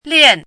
chinese-voice - 汉字语音库
lian4.mp3